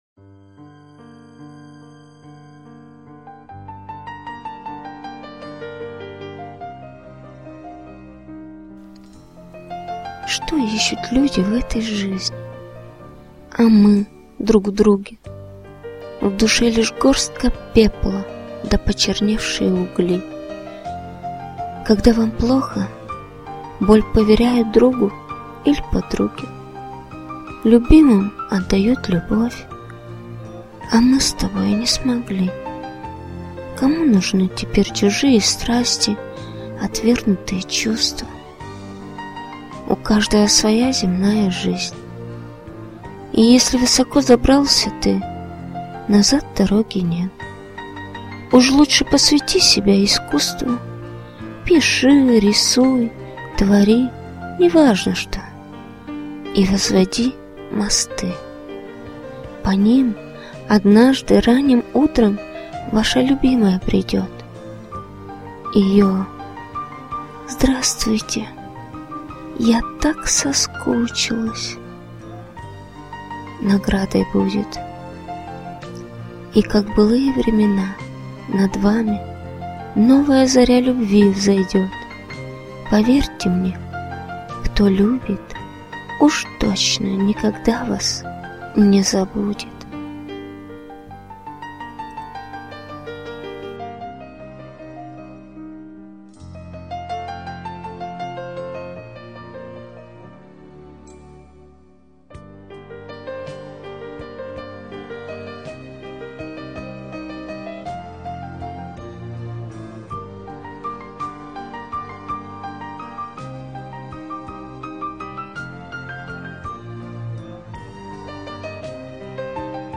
Незвичайно гарно скомпували мелодію Шопена і такі чудові слова!